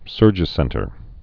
(sûrjĭ-sĕntər)